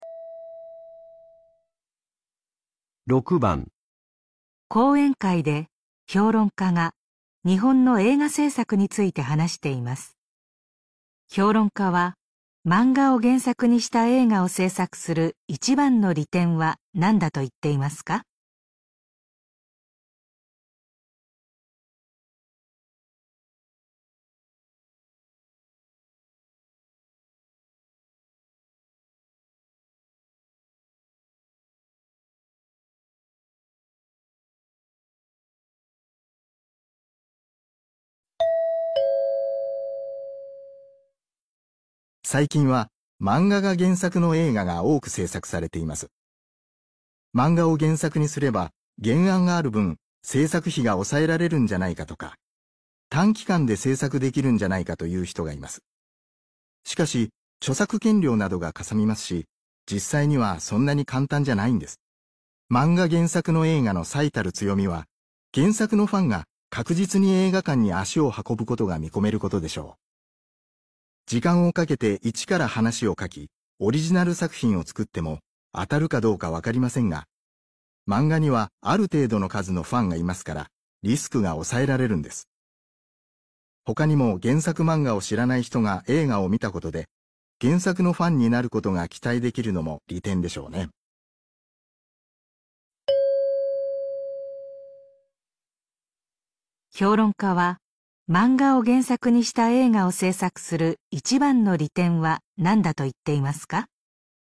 演讲会上，评论家在谈论日本的电影制作。